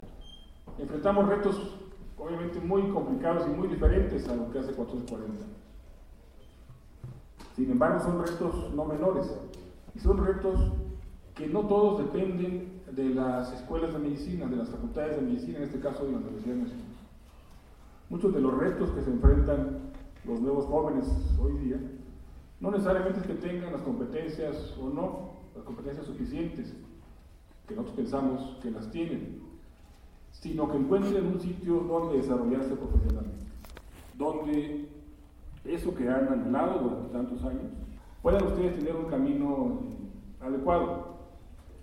En el auditorio Gustavo Baz Prada de la Antigua Escuela de Medicina consideró que los jóvenes que estudian esta área del conocimiento enfrentan retos complicados y diferentes a los de hace 440 años; sin embargo, no son menores y no todos dependen de la Facultad de Medicina de la UNAM.